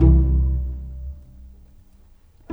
Rock-Pop 09 Pizzicato 04.wav